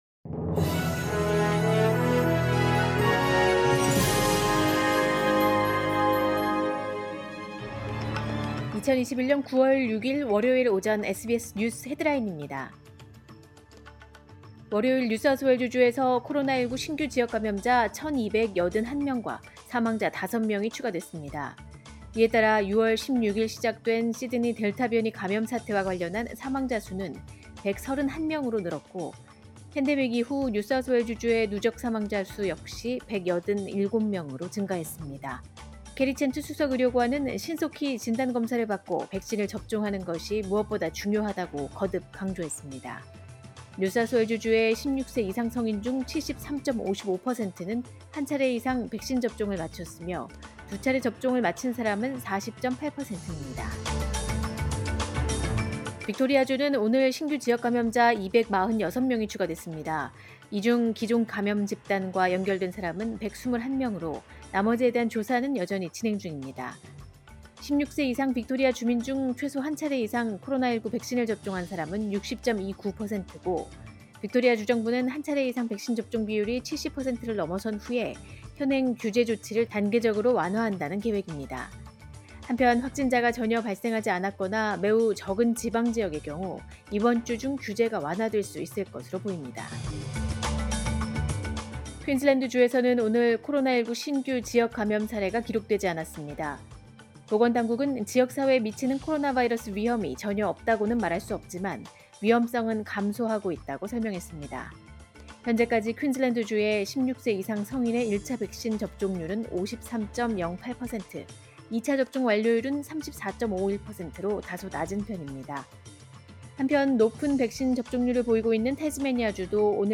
“SBS News Headlines” 2021년 9월 6일 오전 주요 뉴스
2021년 9월 6일 월요일 오전의 SBS 뉴스 헤드라인입니다.